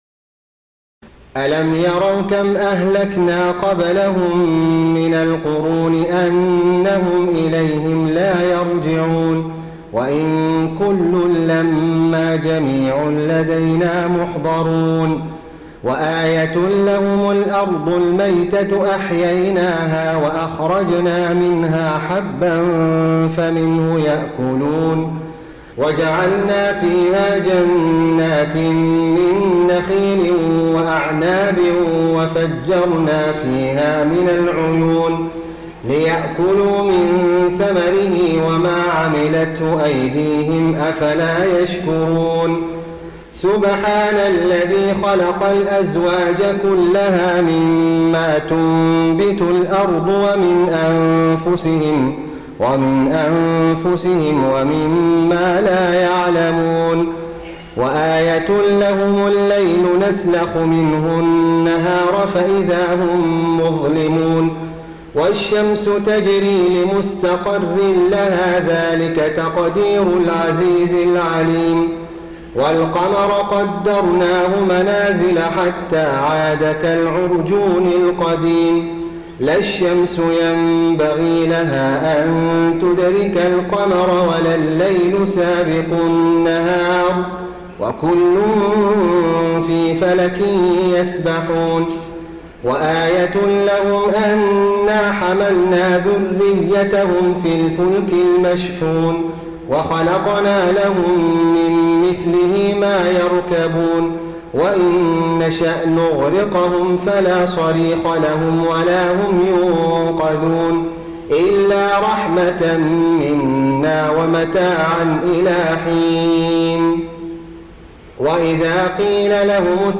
تراويح ليلة 22 رمضان 1433هـ من سور يس (31-83) والصافات(1-148) Taraweeh 22 st night Ramadan 1433H from Surah Yaseen and As-Saaffaat > تراويح الحرم النبوي عام 1433 🕌 > التراويح - تلاوات الحرمين